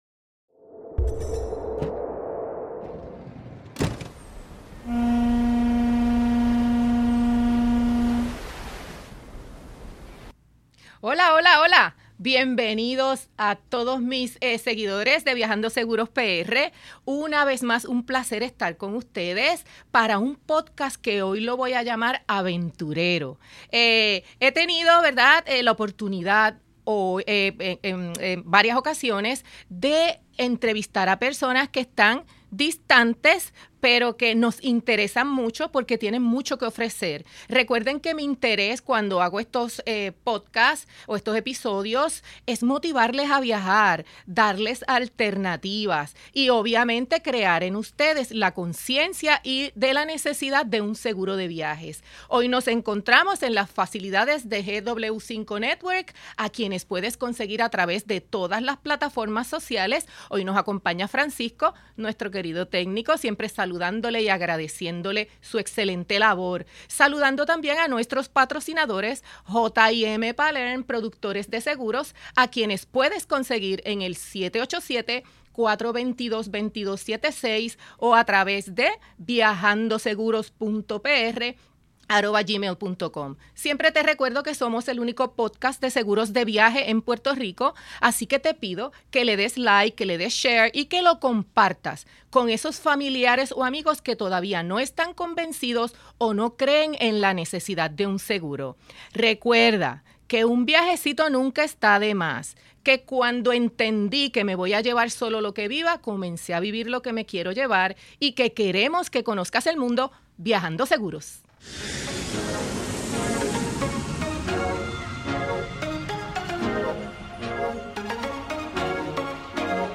Entrevista remoto desde San Martín.